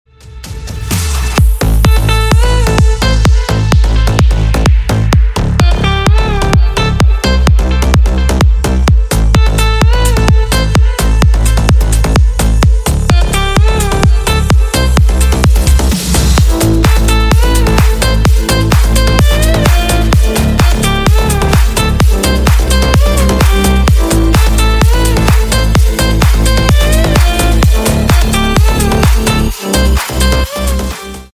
Клубные Рингтоны » # Громкие Рингтоны С Басами
Рингтоны Без Слов
Рингтоны Ремиксы » # Танцевальные Рингтоны